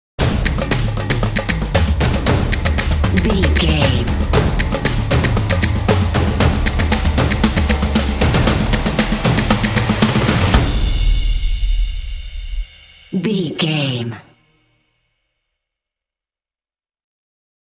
Epic / Action
Atonal
groovy
intense
driving
energetic
drumline